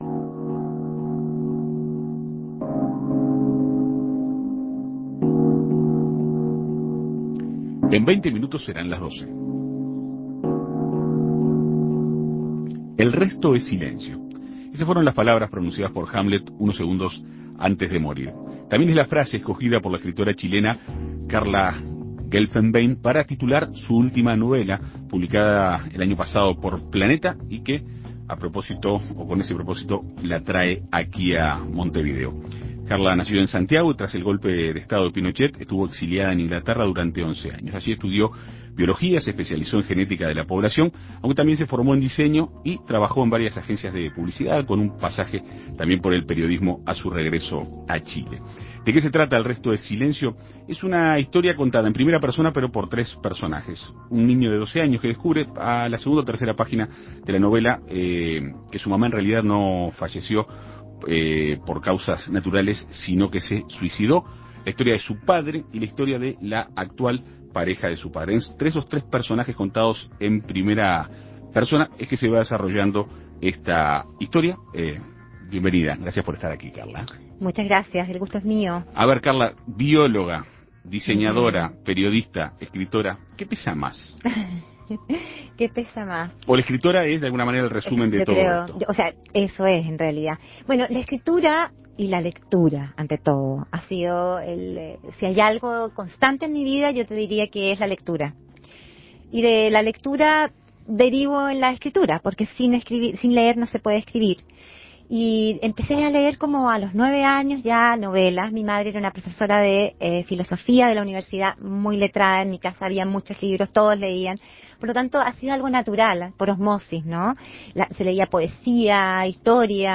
Se trata de una historia con una fuerte presencia de lo no dicho, en un ambiente familiar marcado por la incomunicación. Para conocer de cerca los pormenores de la obra, En Perspectiva Segunda Mañana dialogó con la autora.